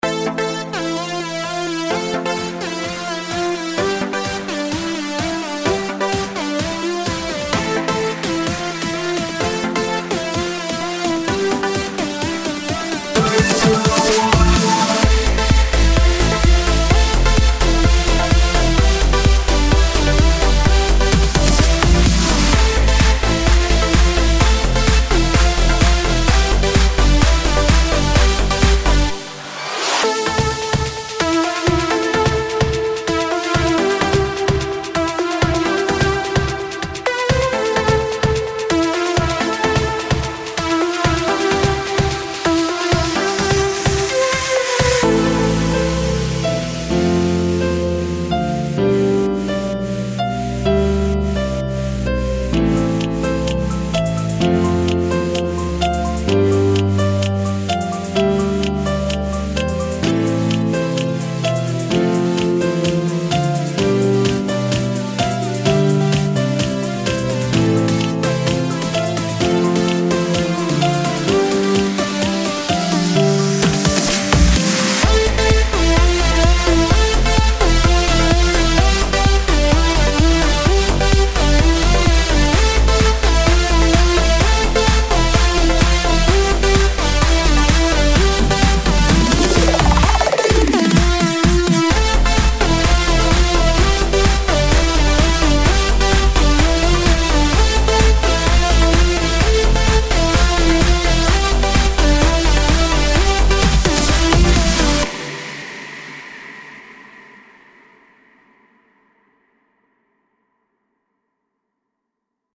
אני הייתי מתחיל את זה לא ב-בום, תעשה חימום קטן לאוזן לקראת הכניסה ואז תיכנס.